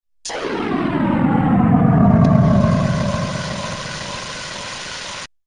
Звуки взрывов разные
Взрыв баллистической ракеты в воздухе
vzryv-ballisticheskoi-rakety-v-vozdukhe.mp3